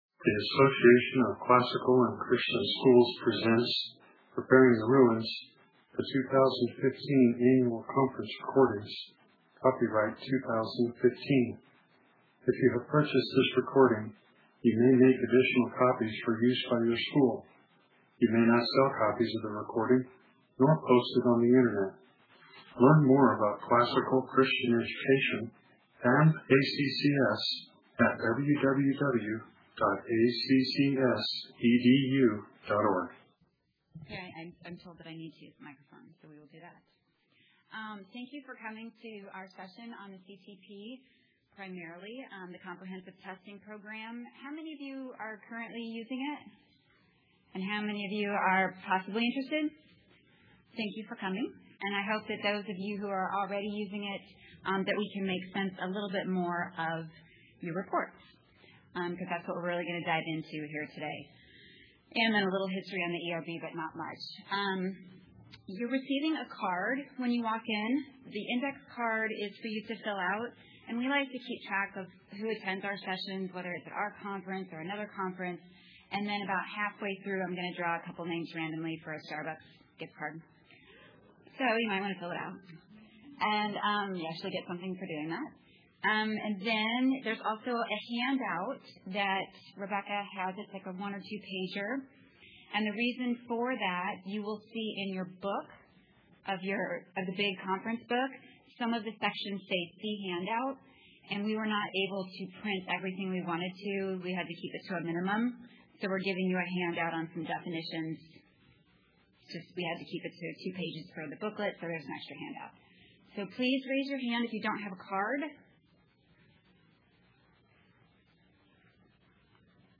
2015 Workshop Talk | 1:04:21 | All Grade Levels